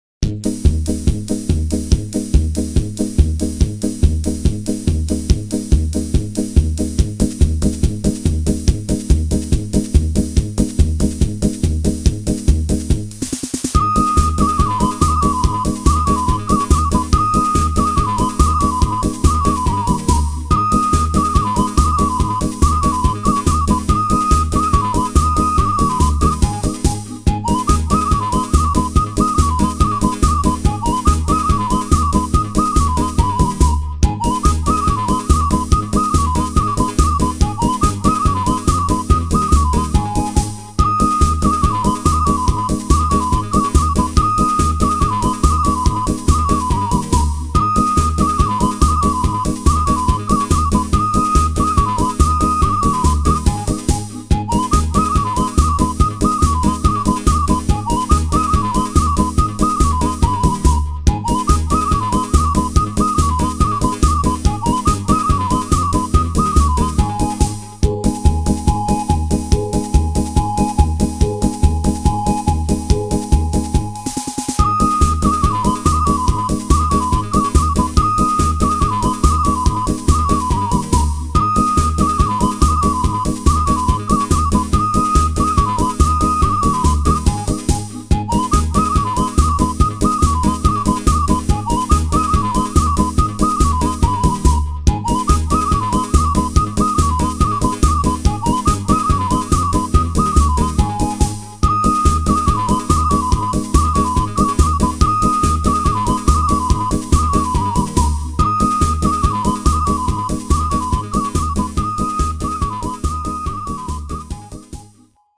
شاد